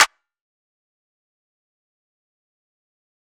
{CLAP} (2).wav